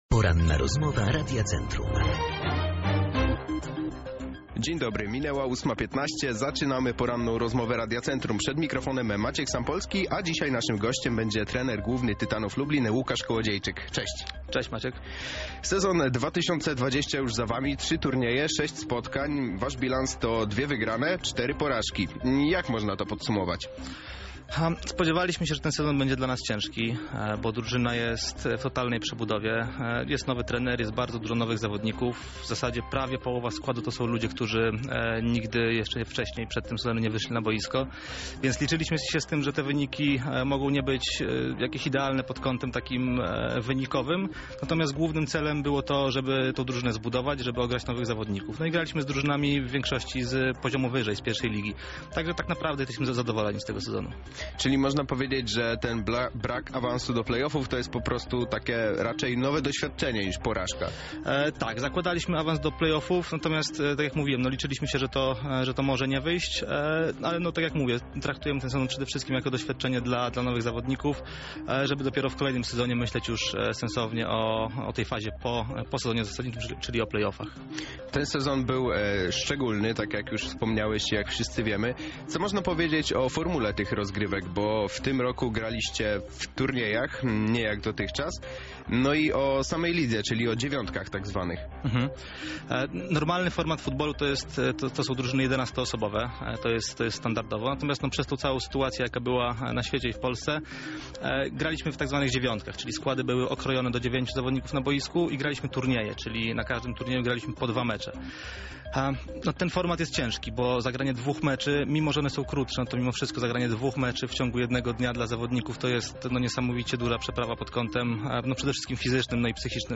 Cała rozmowa dostępna jest tutaj: